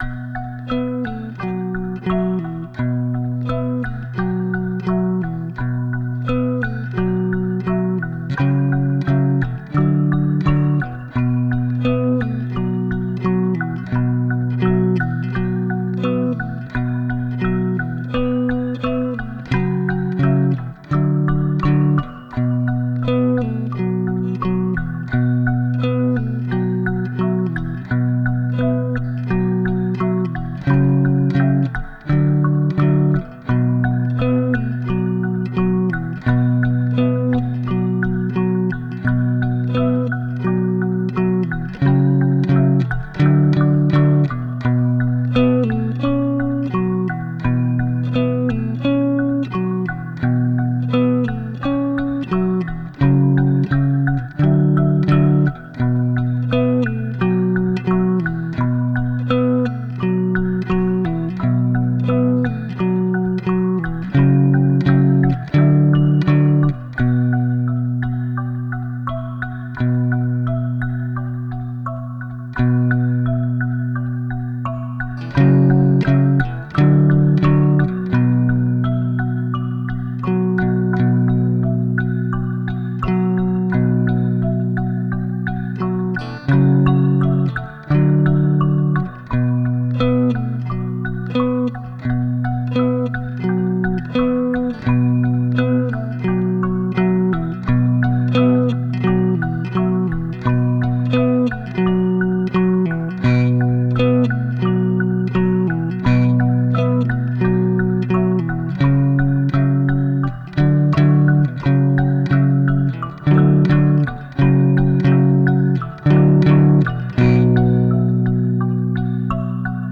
[Bac à sable] Impro guitare 01
ça m'a inspiré une petite composition d'ambiance.
J'ai rajouté neuf pistes que j'ai groupé en cinq plus les deux d'origines que j'ai adapté à la duré du morceau avec une petite reverb sur la guitare à la fin du morceau.